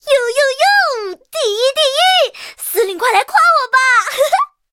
M4A3E2小飞象MVP语音.OGG